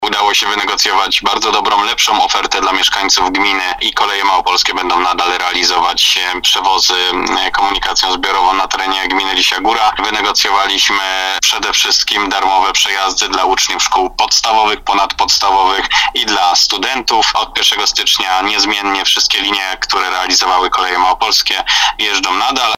Jak poinformował wójt Lisiej Góry Arkadiusz Mikuła, przewozy nadal realizowane będą przez Koleje Małopolskie.